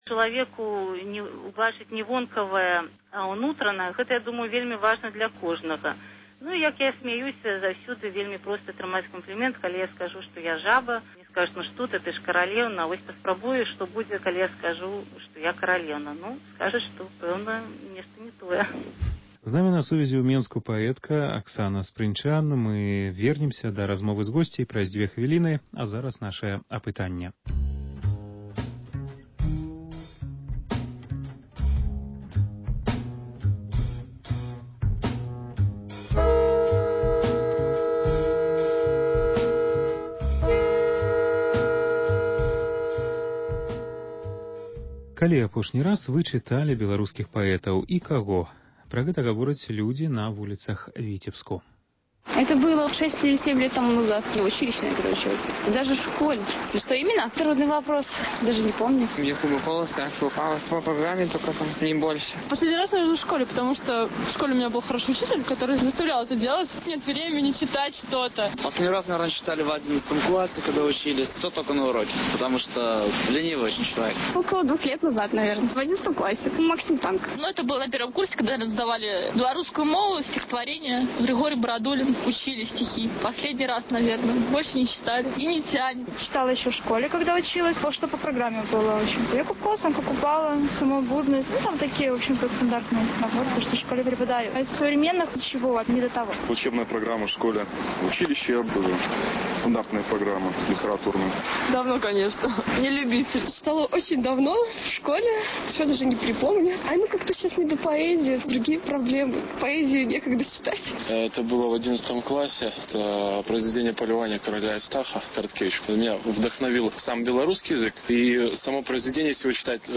гутарыць з выдаўцом